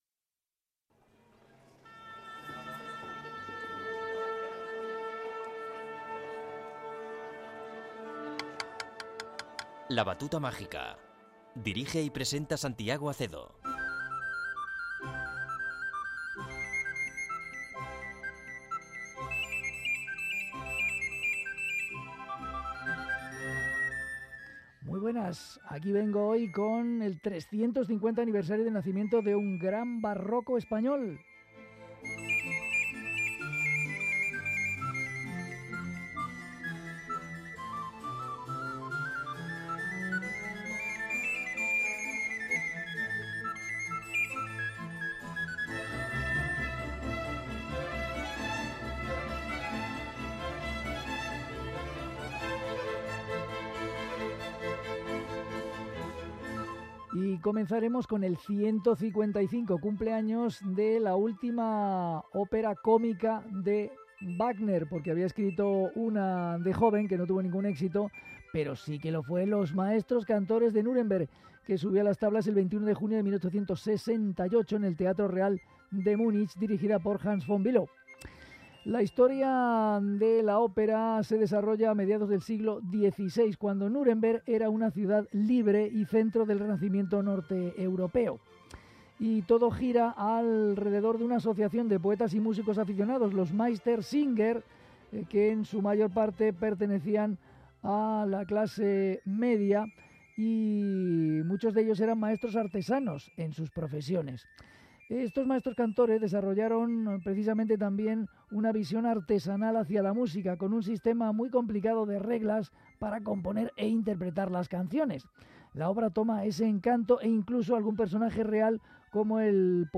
Aria con violín
zarzuela barroca
Obertura
Concierto para 2 oboes en Si menor